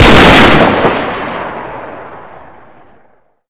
svd_shoot.ogg